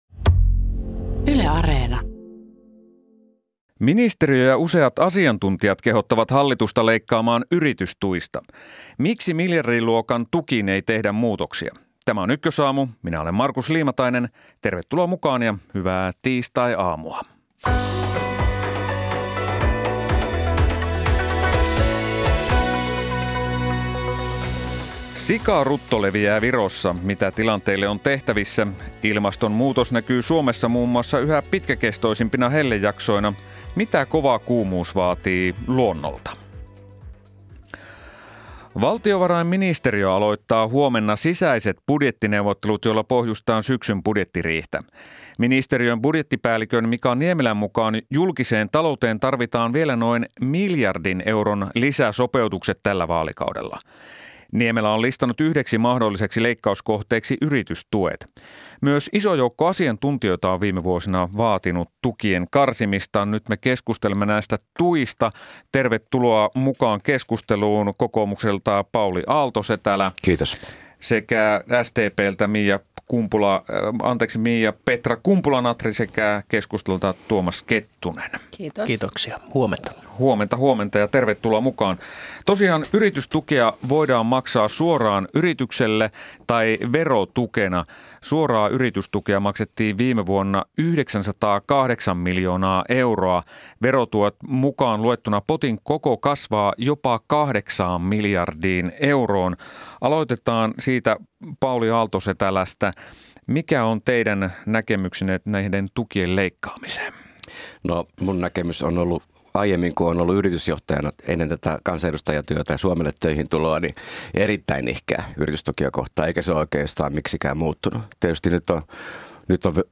Ajankohtaista politiikkaa ja taloutta Suomessa ja maailmalla. Eturivin haastateltavat ja terävimmät kysymykset.